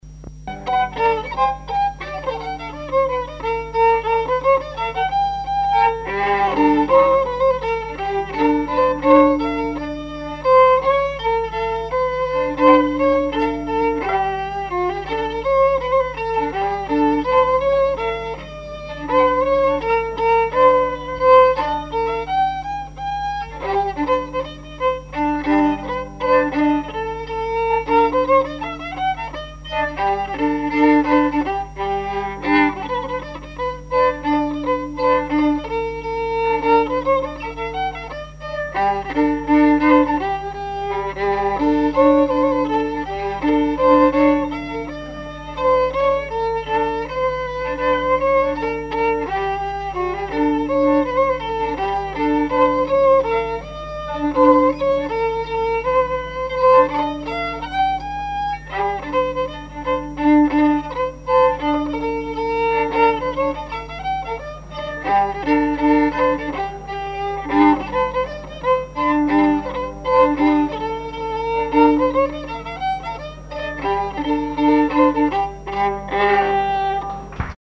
danse : paskovia
collectif de musiciens pour une animation à Sigournais
Pièce musicale inédite